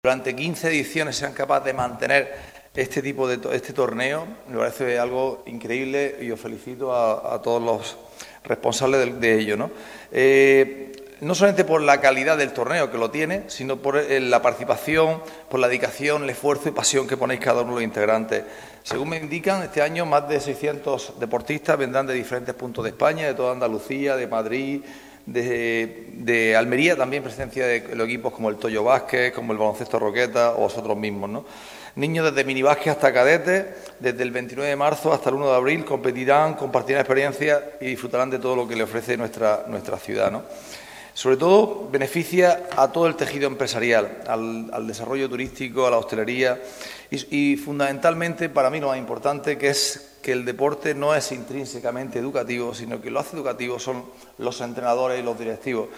El concejal Antonio Casimiro destaca, en la presentación “el valor deportivo y social, así como los beneficios económicos” de la competición que se celebrará en El Toyo
En la rueda de prensa también se ha incidido en el valor educativo del torneo, destacando el concejal que “el deporte es una herramienta fundamental para transmitir valores como el respeto, la convivencia, el trabajo en equipo y el juego limpio. Esa es la verdadera victoria que debemos perseguir como sociedad”.
ANTONIO-JESUS-CASIMIRO-CONCEJAL-CIUDAD-ACTIVA-TORNEO-ADABA.mp3